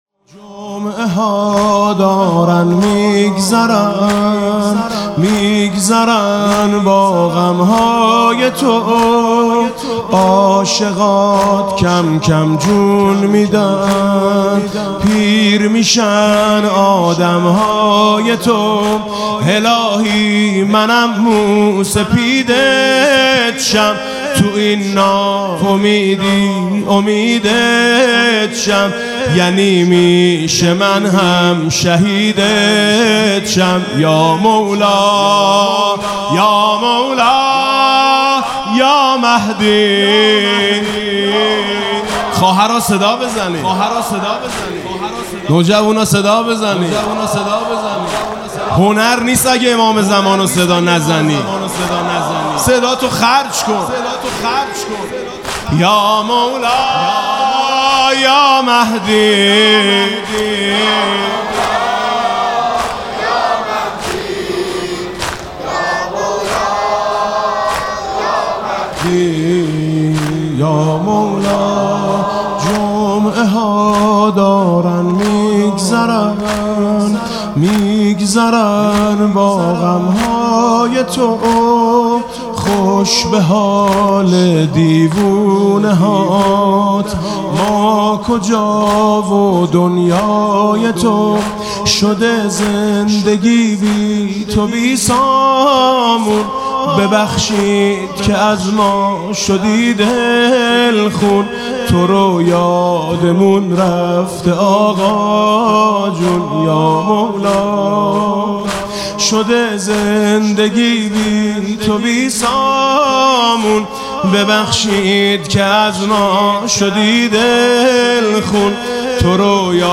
زمینه گفتگو با امام زمان